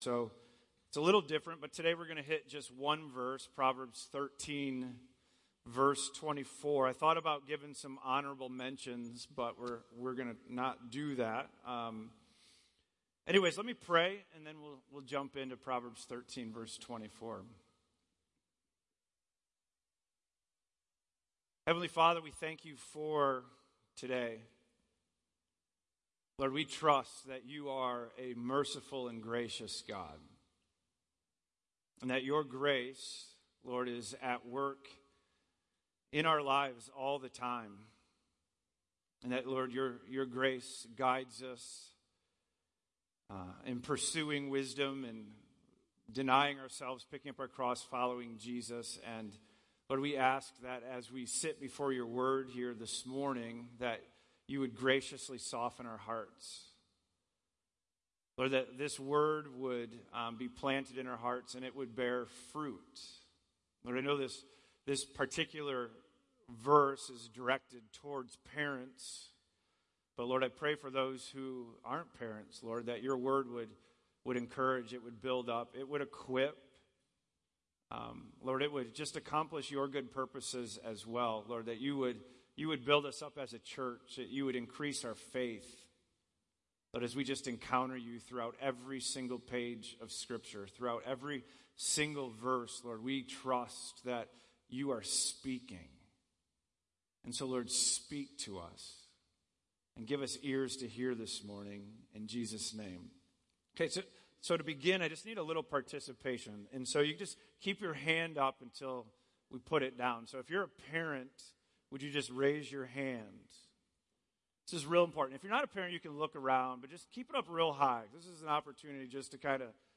Sermons from the pulpit of Sovereign Grace Church in Woodstock, GA.